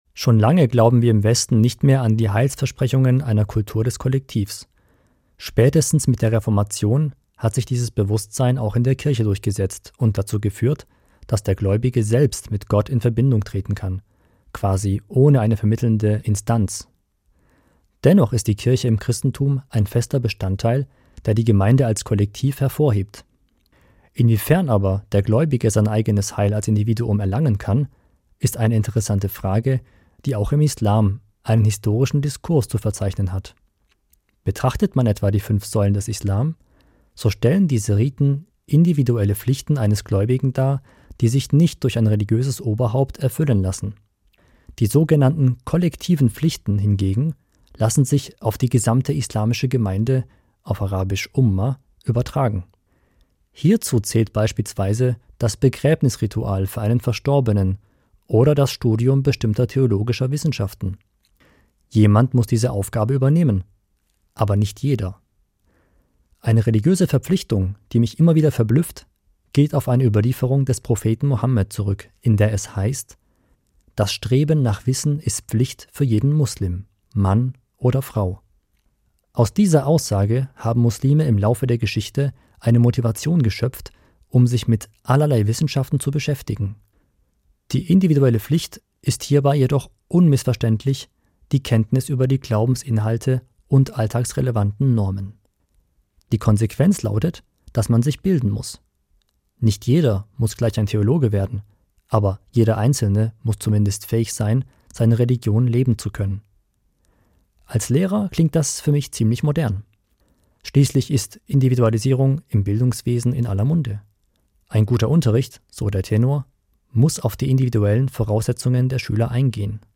Was glauben Muslime - und wie prägt dieser Glaube das Leben der Gläubigen? Darüber geben muslimische Männer und Frauen Auskunft im "Islam in Deutschland".